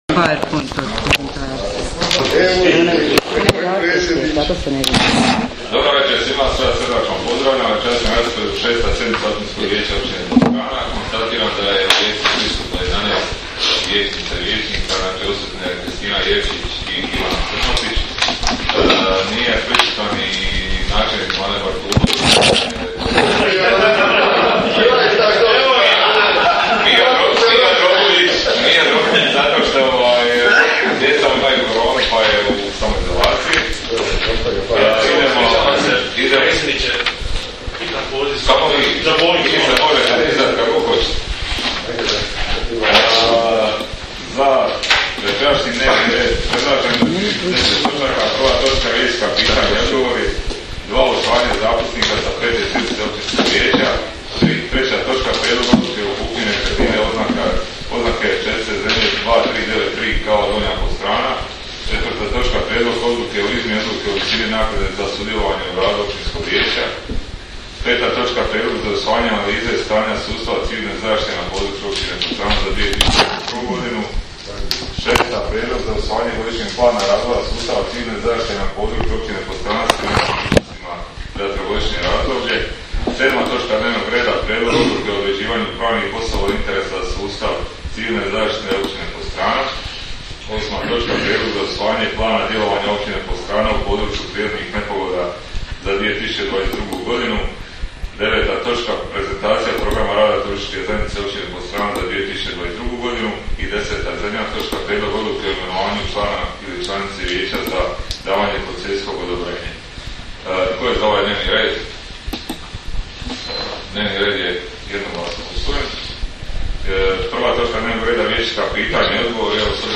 Sjednica će se održati dana 26. siječnja (srijeda) 2022. godine u 18,00 sati u Sali za sastanke Općine Podstrana.